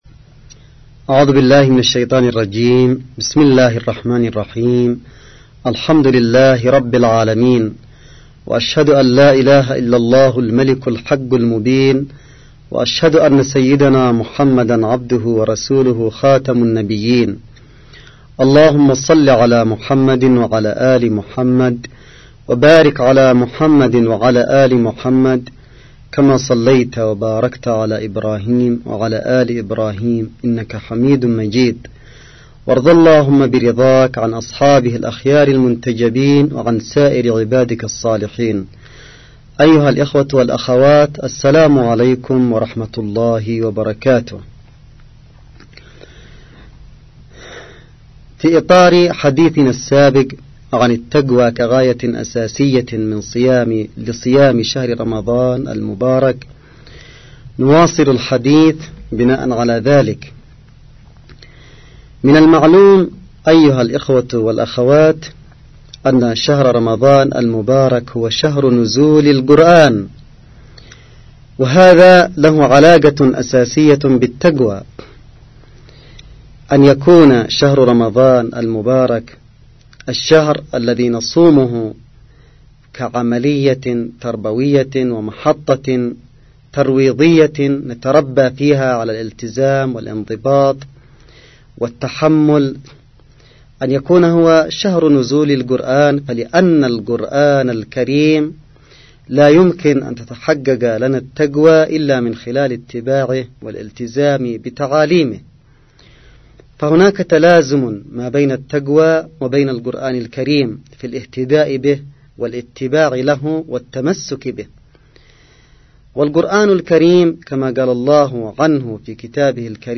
محاضرة_السيد_عبدالملك_بدر_الدين2.mp3